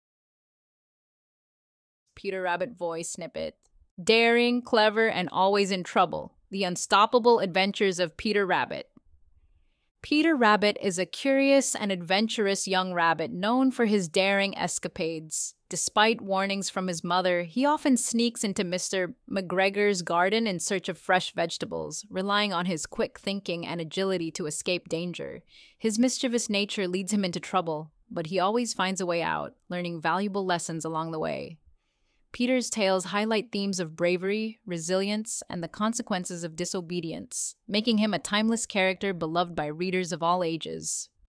Peter Rabbit – Original Tale – audiobookz
Peter_Rabbit_voice-snippet-1.mp3